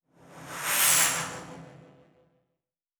Sci-Fi Sounds / Movement / Fly By 07_6.wav
Fly By 07_6.wav